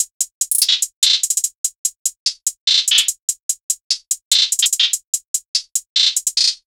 kits/Southside/Closed Hats/HH LOOP - 146bpm SIZZLE GOAT.wav at main
HH LOOP - 146bpm SIZZLE GOAT.wav